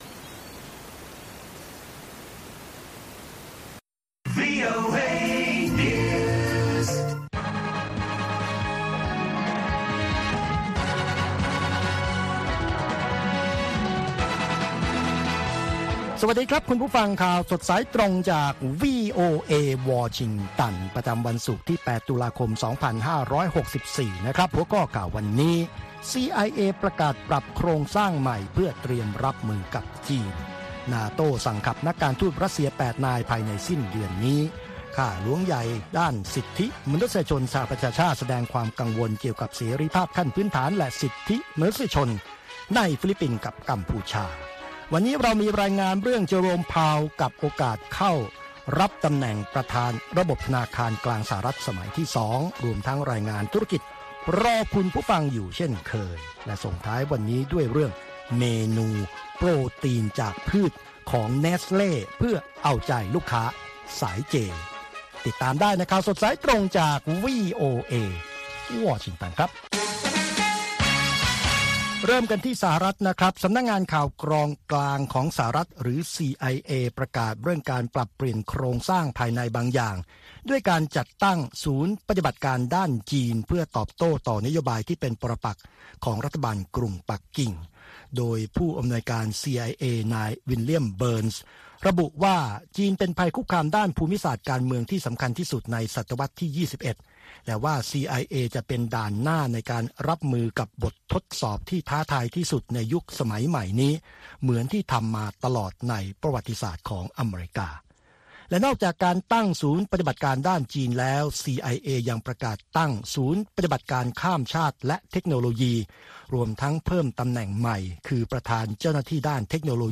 ข่าวสดสายตรงจากวีโอเอ ภาคภาษาไทย ประจำวันศุกร์ที่ 8 ตุลาคม 2564 ตามเวลาประเทศไทย